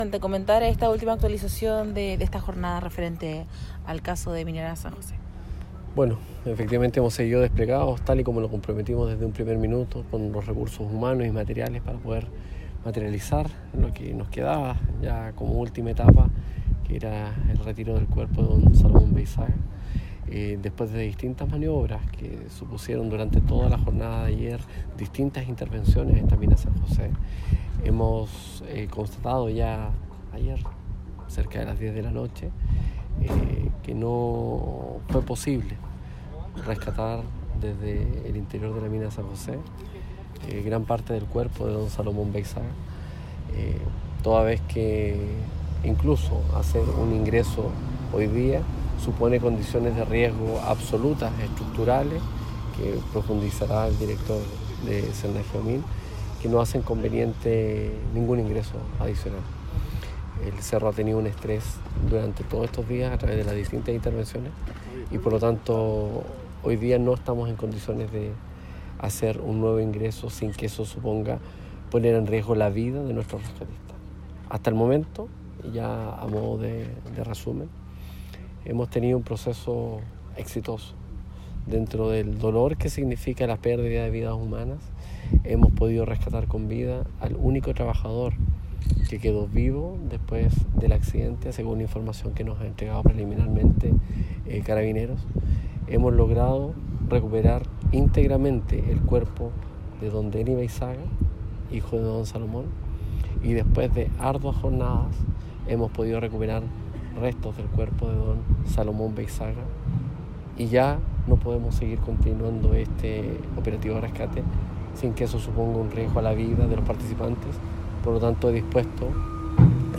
Audio Marco Antonio Diaz – Intendente Región de Antofagasta